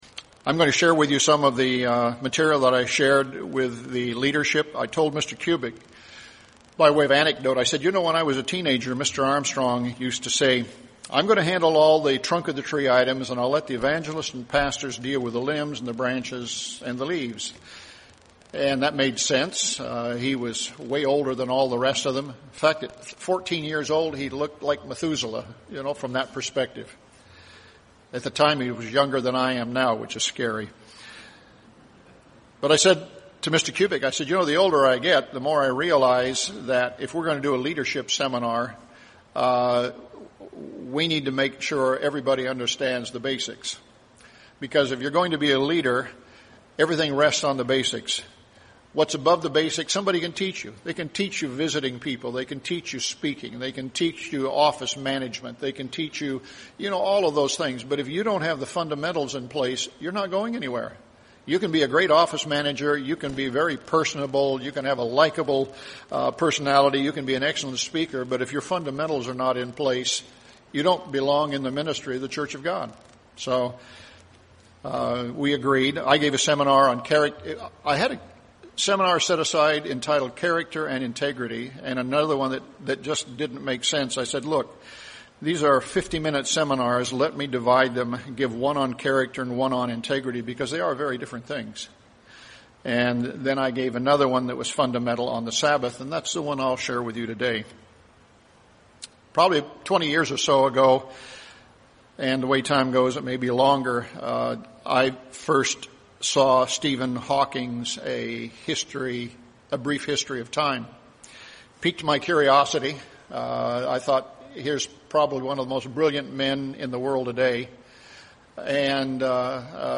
This sermon explores the universal quality that governs the spiritual success or failure of both men and angels.